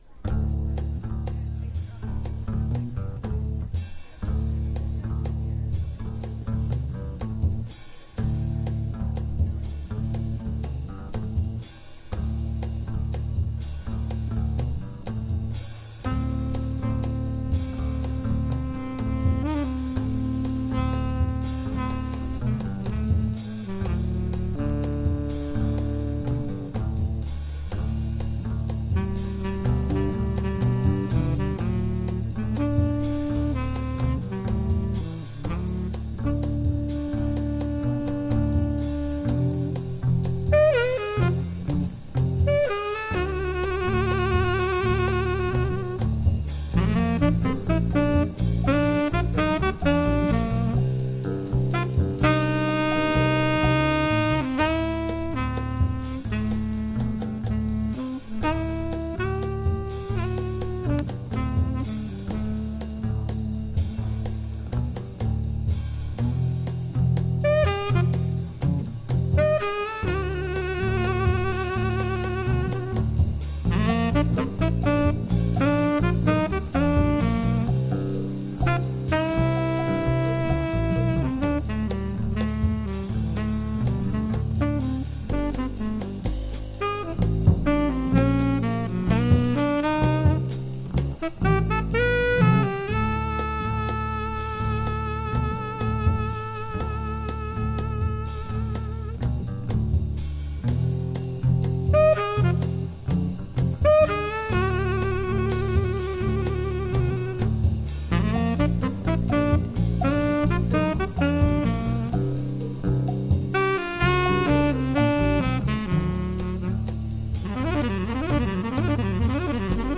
（プレスによりチリ、プチ音ある曲あり）
Genre US JAZZ